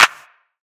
SOUTHSIDE_clap_nice_room.wav